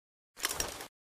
holster.ogg